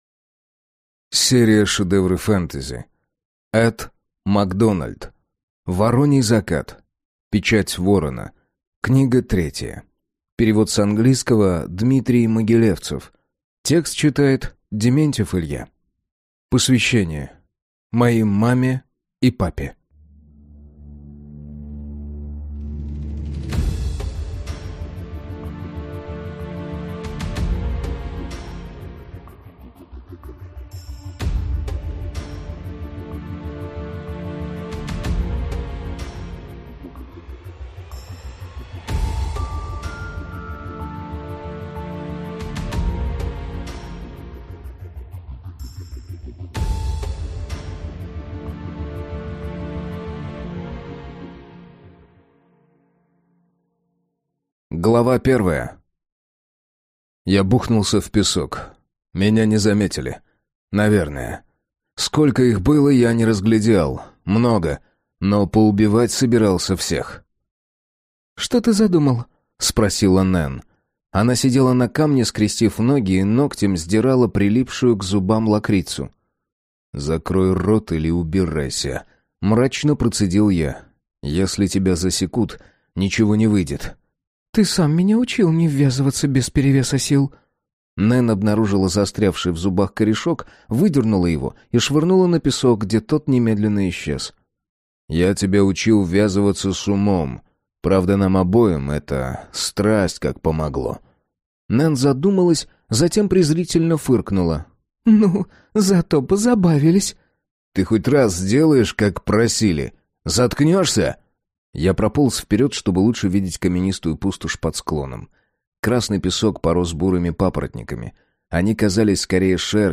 Аудиокнига Вороний закат | Библиотека аудиокниг